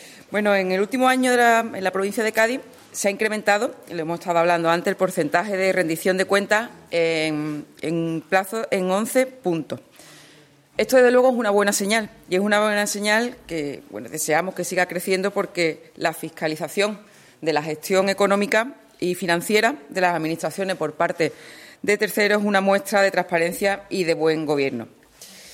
Tanto en sus declaraciones a los medios como durante la jornada, Almudena Martínez ha insistido en que la “fiscalización de la gestión económica y financiera de las administraciones por parte de terceros es una muestra de transparencia y buen gobierno”.
Corte de Almudena Martínez